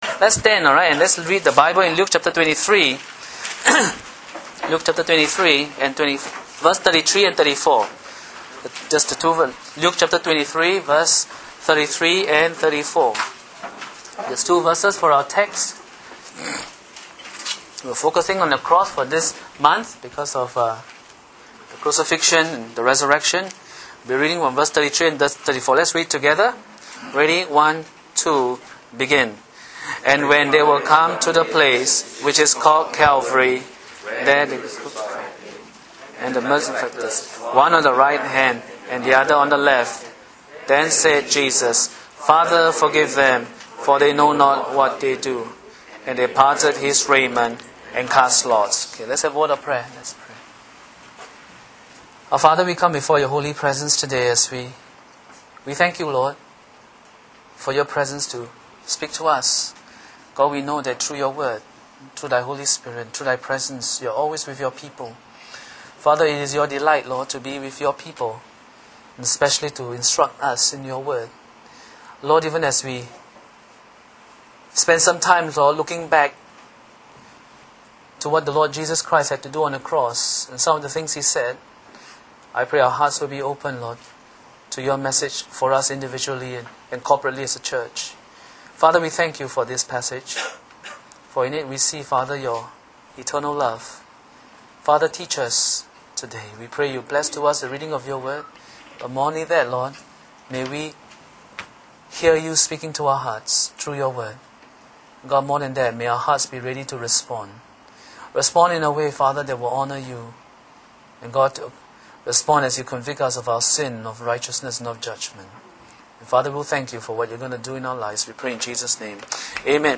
Message truncated due to technical errors.